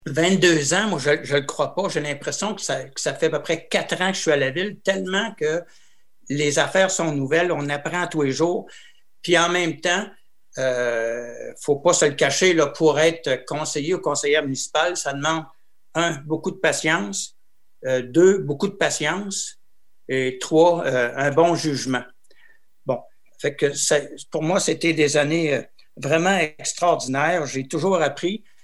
M. Gagné a ainsi parlé de son expérience.